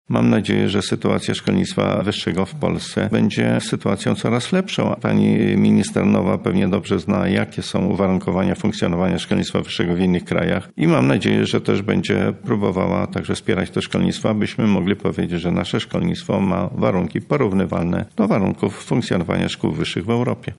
KOMENTARZ-KOLARSKA-BOBIŃSKA.mp3